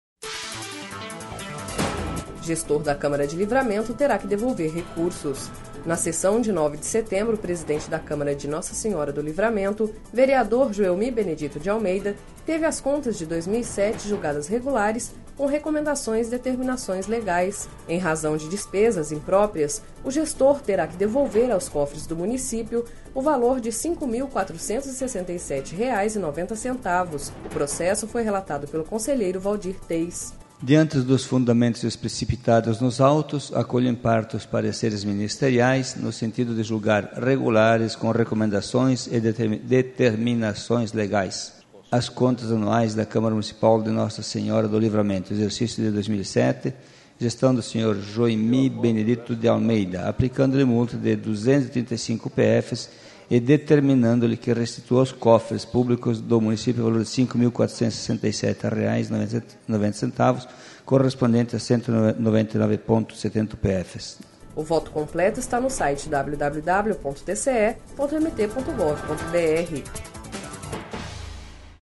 Sonora: Waldir Teis– conselheiro do TCE-MT